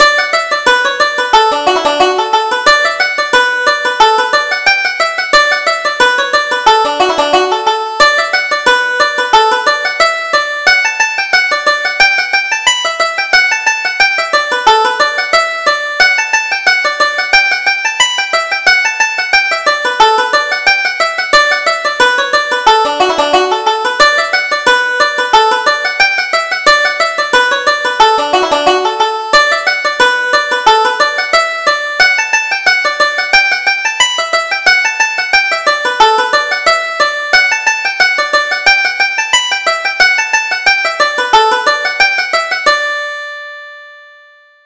Reel: Johnny with the Queer Thing